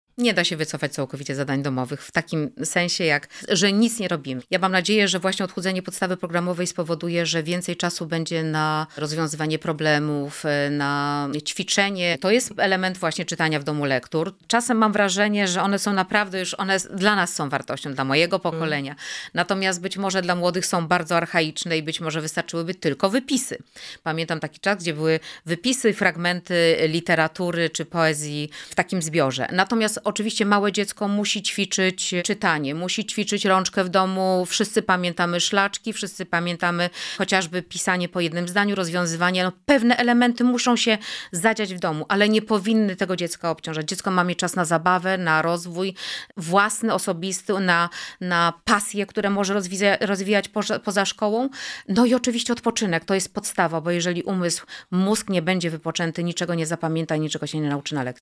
-Nie da się wycofać całkowicie zadań domowych, mówi p.o. kuratora.